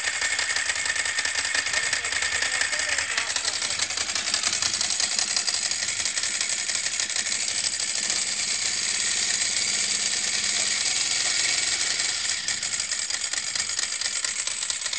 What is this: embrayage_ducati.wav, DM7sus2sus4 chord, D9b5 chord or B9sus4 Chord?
embrayage_ducati.wav